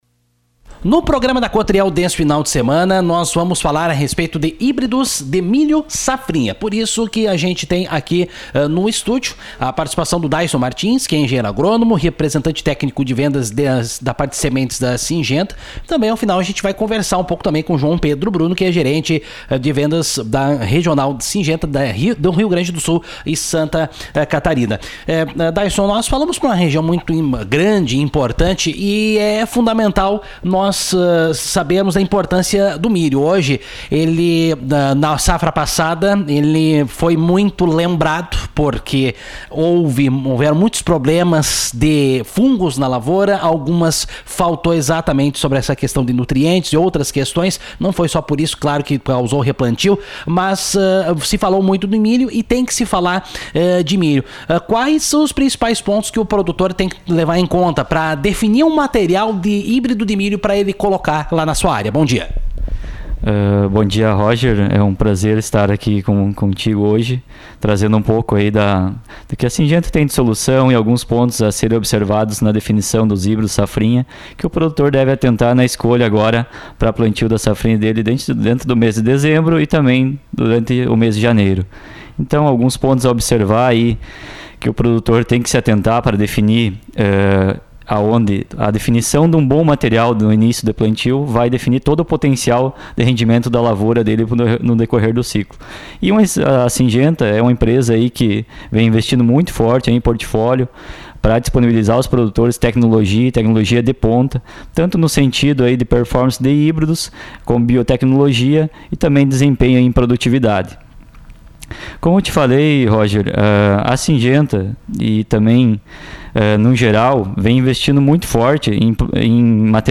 estiveram em visita aos nossos estúdios falando de milho safrinha.